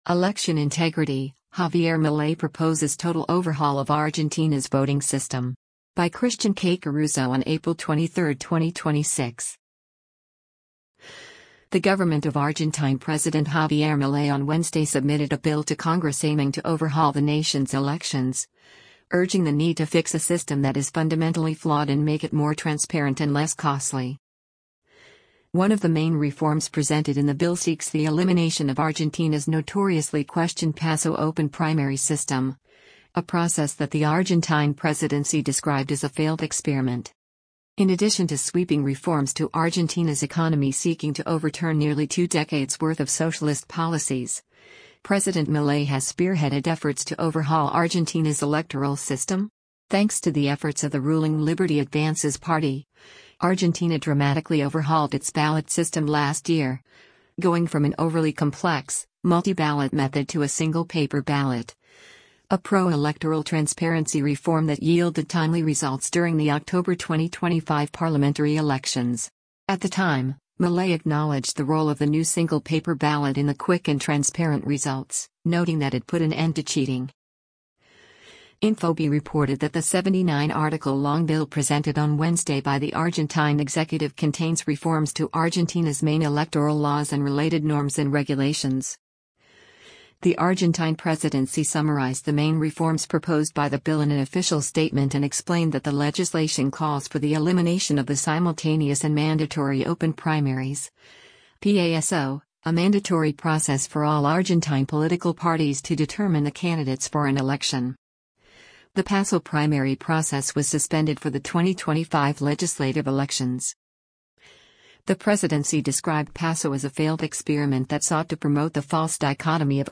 The President delivers a speech recognizing veterans and reaffirming the sovereignty claim